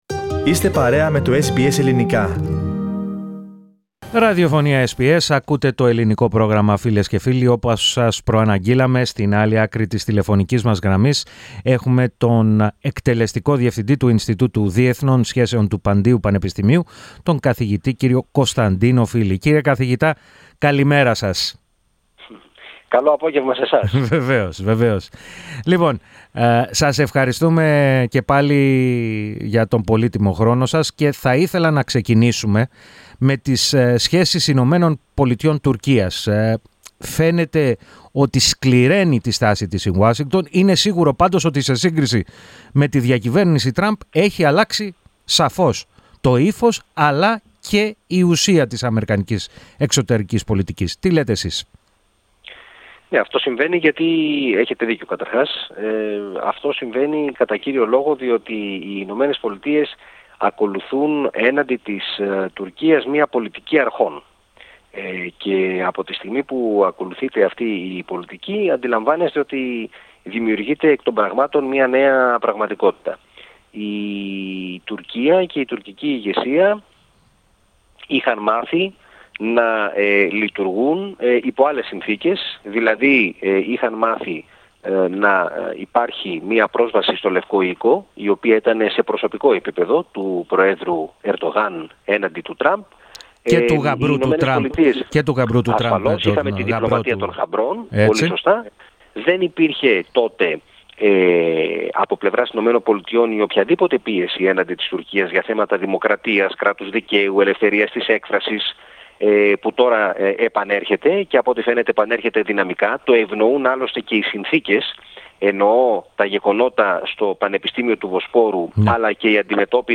Ακούστε ολόκληρη τη συνέντευξη, πατώντας το σύμβολο στο μέσο της κεντρικής φωτογραφίας.